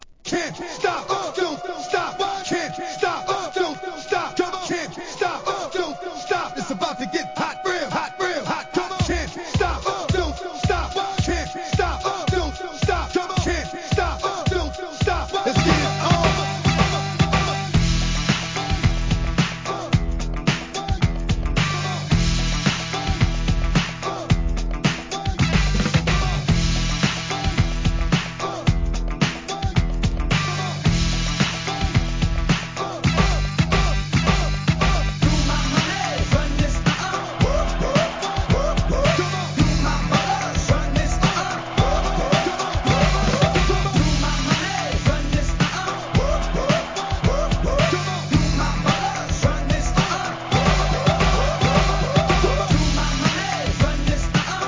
HIP HOP/R&B
PARTY BREAKS